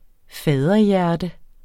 Udtale [ ˈfæːðʌ- ]